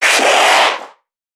NPC_Creatures_Vocalisations_Infected [99].wav